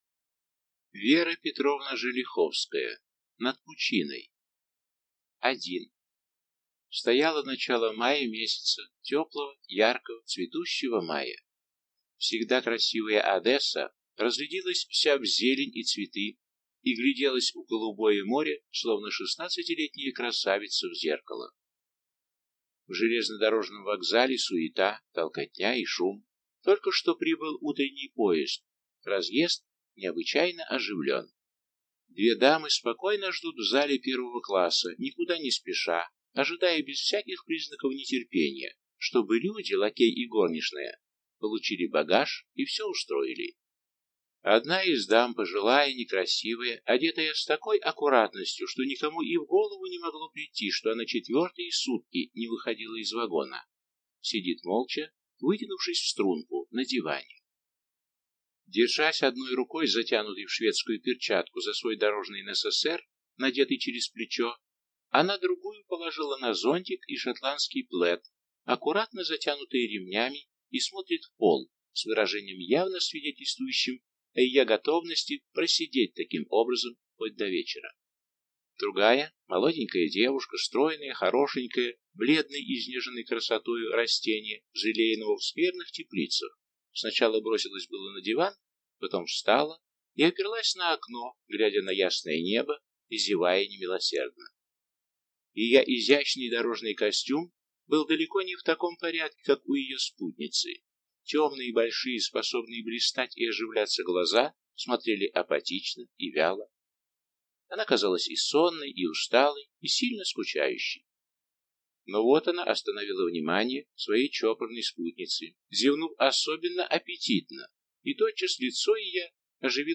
Аудиокнига Над пучиной | Библиотека аудиокниг